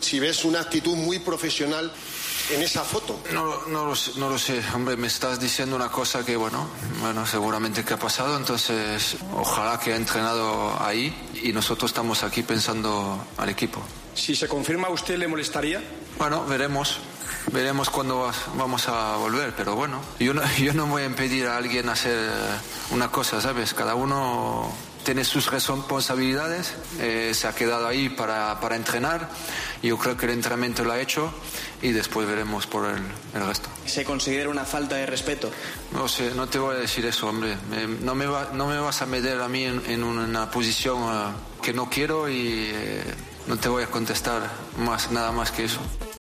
Zinedine Zidane, entrenador del Real Madrid, aseguró este miércoles en rueda de prensa, después de ganar 5-3 al Fenerbahçe turco, que "no voy a impedir a alguien hacer una cosa, cada uno tiene sus responsabilidades" en relación a la fotografía de Gareth Bale jugando al golf que ha publicado el diario El Confidencial.